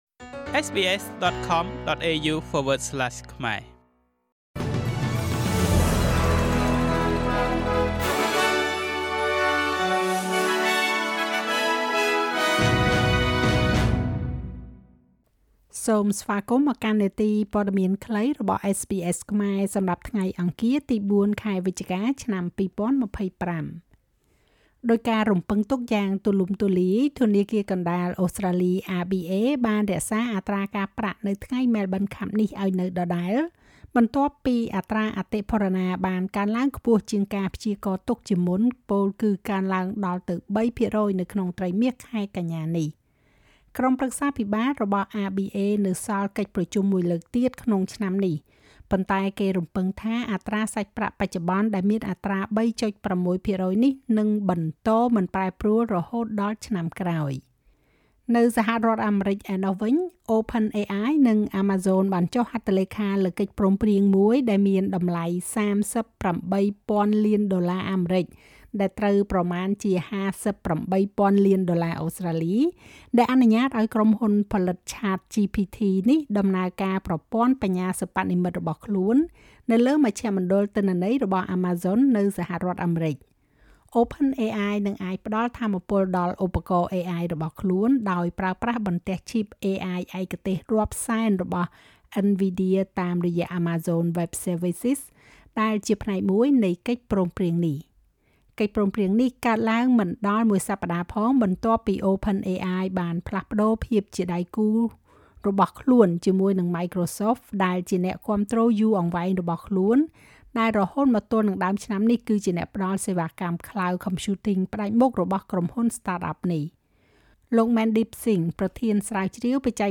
នាទីព័ត៌មានខ្លីរបស់SBSខ្មែរ សម្រាប់ថ្ងៃអង្គារ ទី៤ ខែវិច្ឆកា ឆ្នាំ២០២៥